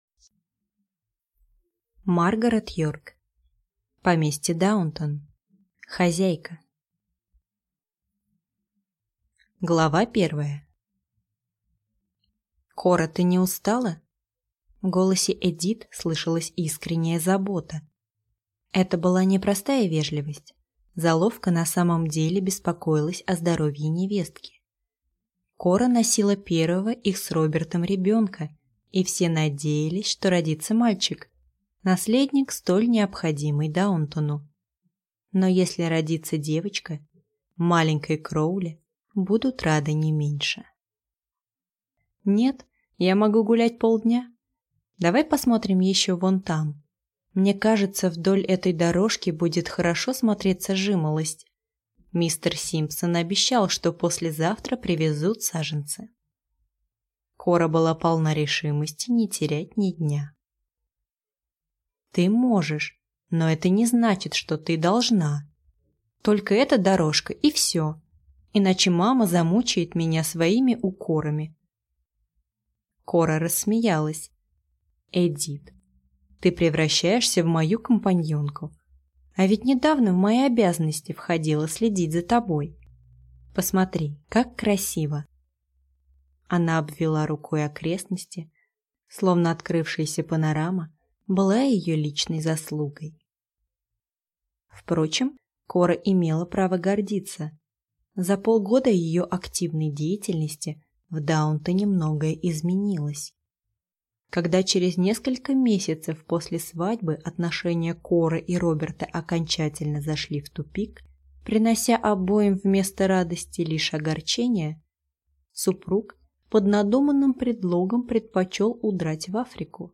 Аудиокнига Поместье Даунтон: Хозяйка | Библиотека аудиокниг